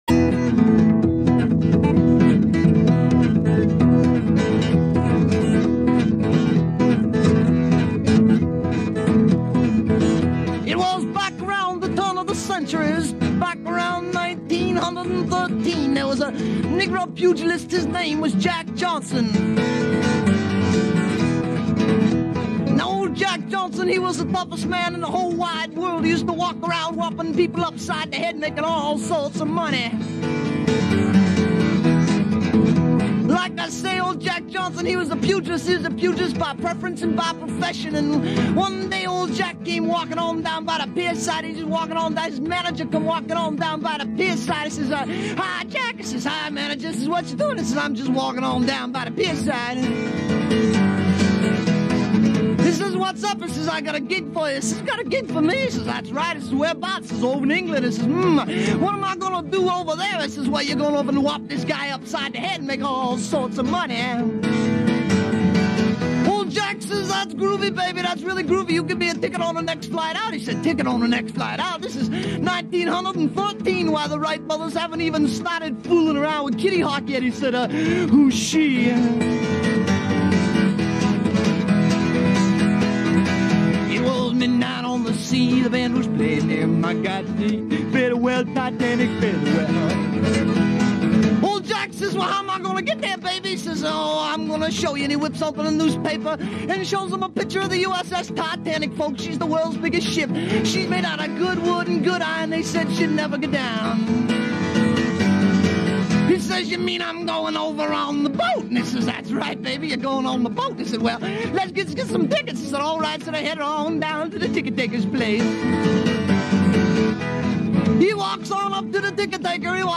late night underground cult classic